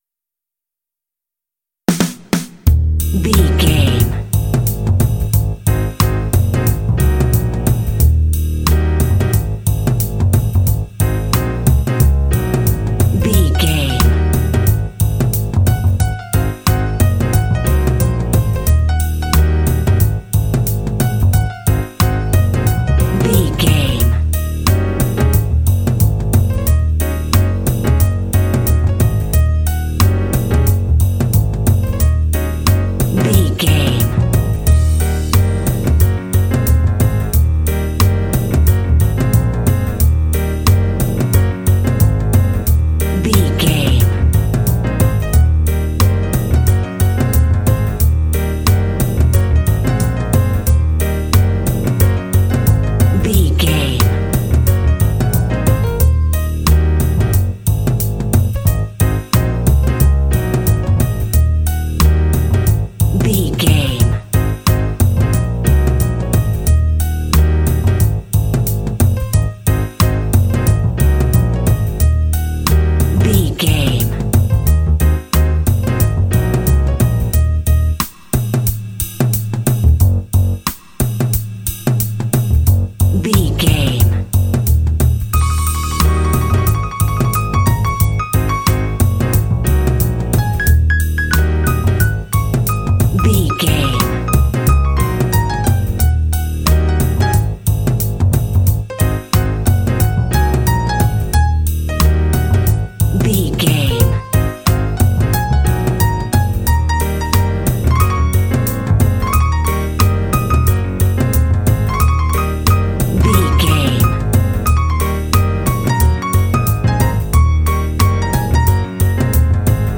Easy, slow-medium jazz trio groove.
Uplifting
Aeolian/Minor
E♭
smooth
double bass
drums
piano